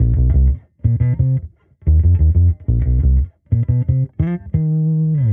Index of /musicradar/sampled-funk-soul-samples/90bpm/Bass
SSF_PBassProc1_90C.wav